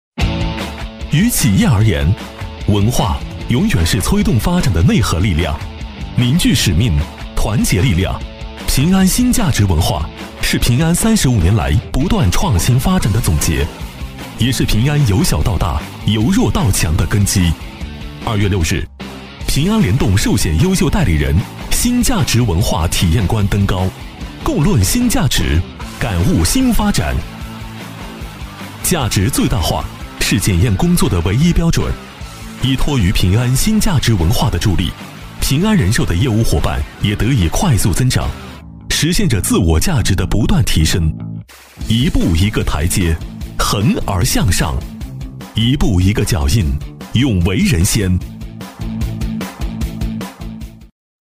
男165-旁白-【 年轻 质感】
男165-磁性质感 年轻时尚
男165-旁白-【 年轻 质感】.mp3